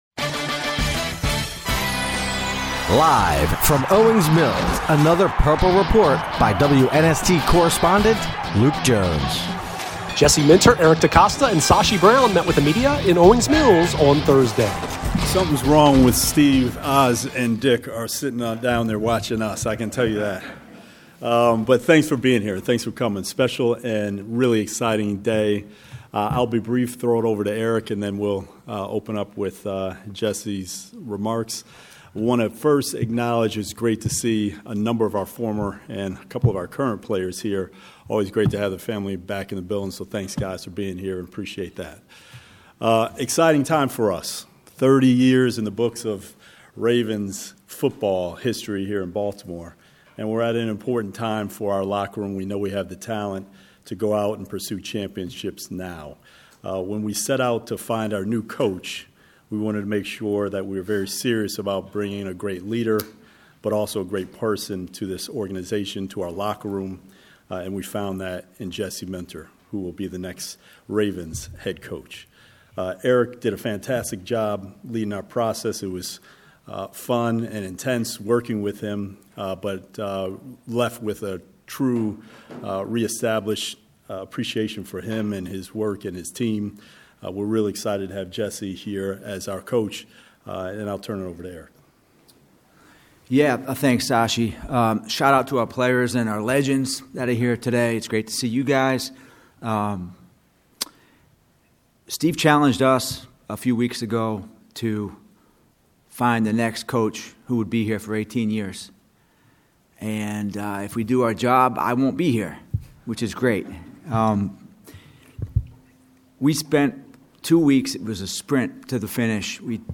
Jesse Minter, Eric DeCosta, Sashi Brown meet with media in Owings Mills